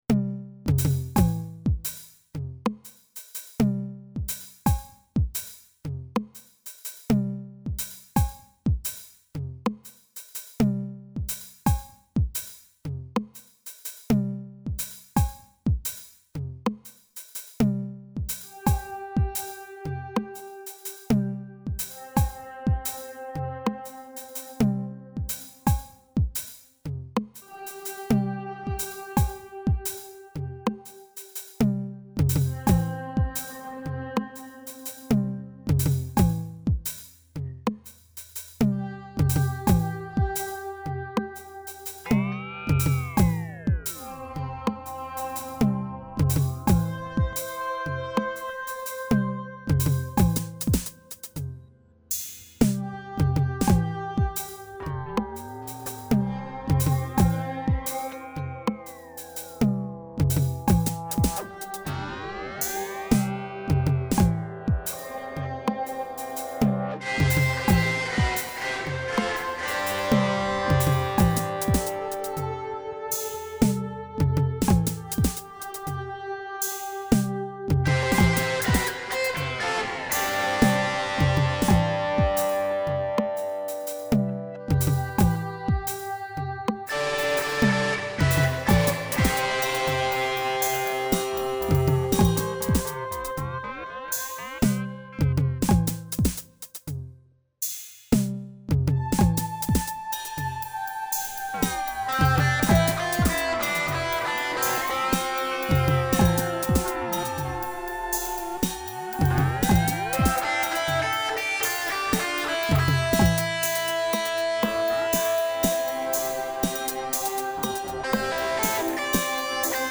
Even more bizarre than the first, if that's possible.
I'm especially proud of the strange drum track on this one which took several hours just to program. The 7-beat time signature kept me on my toes while laying down the guitar. And it's all guitar, every sound except for a 5-second synth pan flute in the middle of the song. So other than that, if it sounds like keyboards, it still ain't. Here's the MP3 for your listening... pleasure?